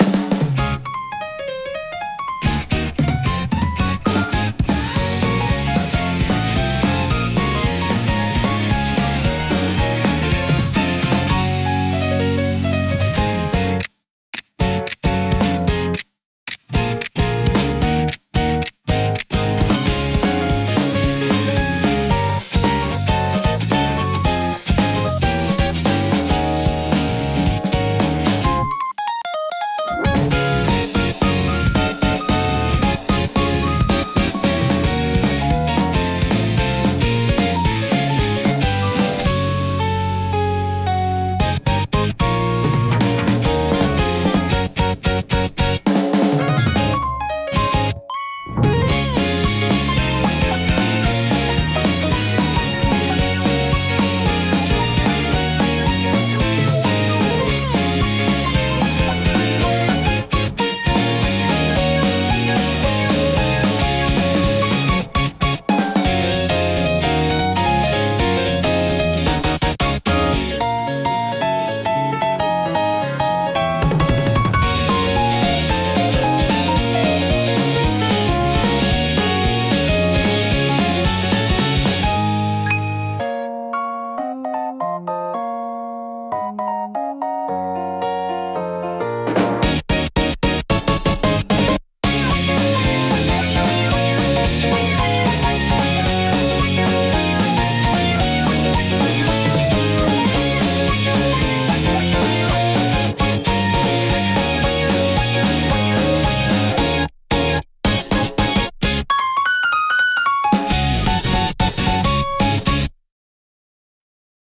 元気で前向きな印象を与え、若年層やファミリー向けコンテンツに特によく合います。